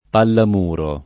vai all'elenco alfabetico delle voci ingrandisci il carattere 100% rimpicciolisci il carattere stampa invia tramite posta elettronica codividi su Facebook pallamuro [ pallam 2 ro ] (raro palla a muro [ p # lla a mm 2 ro ]) s. f. (sport.)